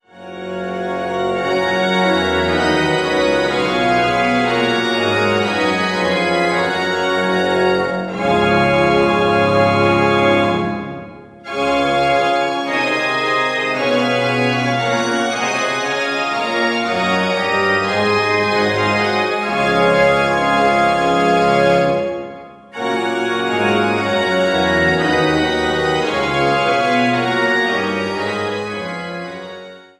Großengottern, Waltershausen, Altenburg, Eisenach